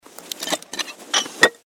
mortarreloadchange.mp3